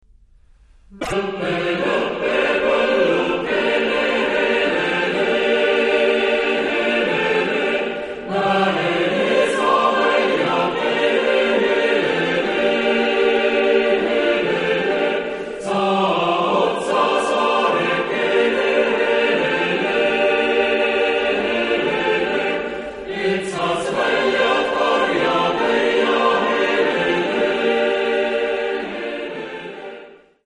Genre-Stil-Form: Chorbearbeitung ; Volkslied ; weltlich
Chorgattung: SATB  (4 gemischter Chor Stimmen )
Tonart(en): g-moll